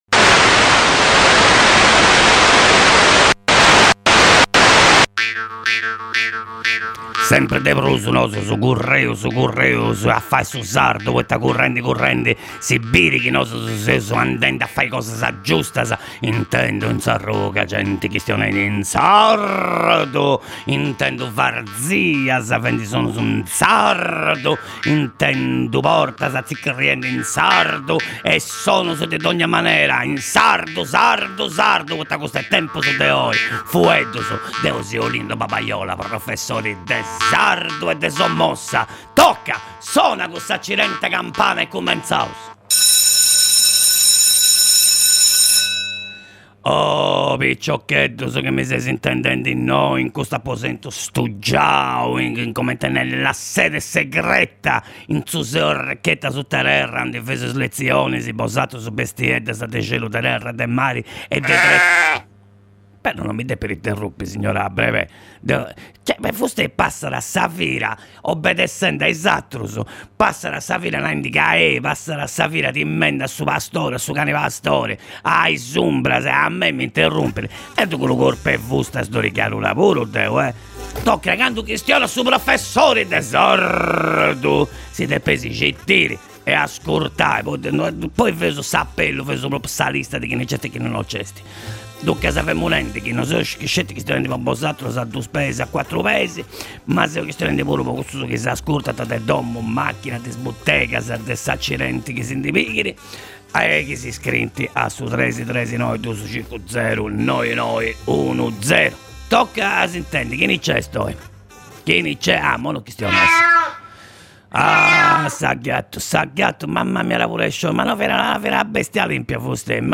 Professor Olindo Babaiola dogna di’ ìntrat a fura in is undas de Radio X po fai scola de sardu.